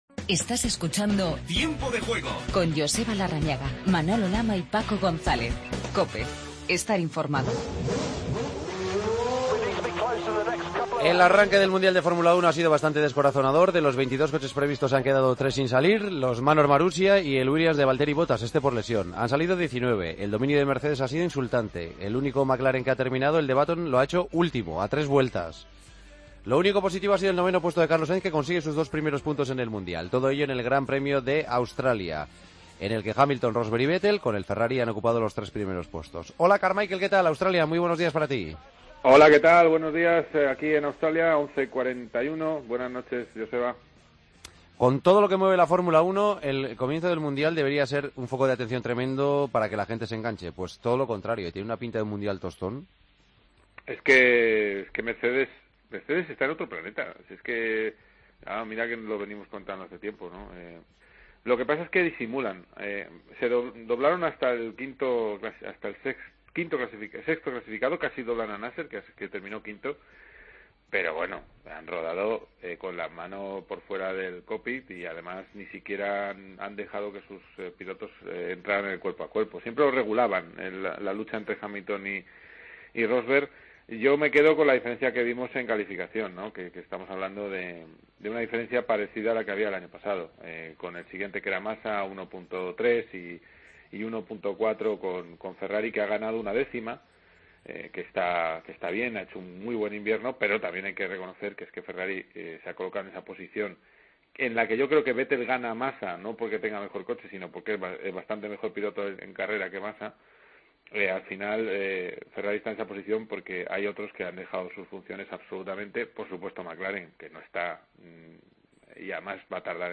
AUDIO: Noticias del GP de Australia de Fórmula 1. Entrevista a Carlos Sáinz.